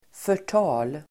Uttal: [för_t'a:l]